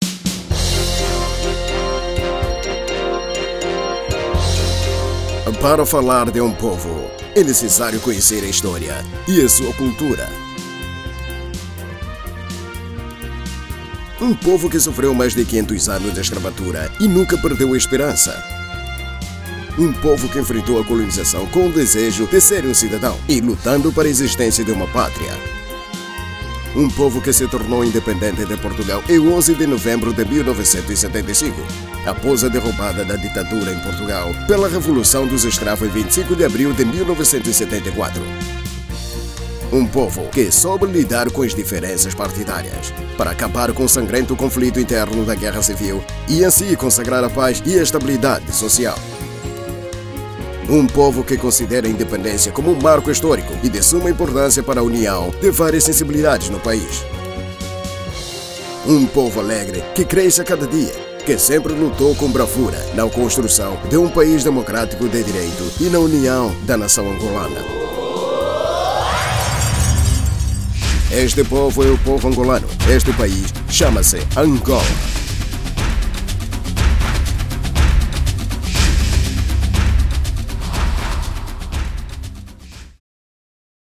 Masculino
Voz Padrão - Grave 01:24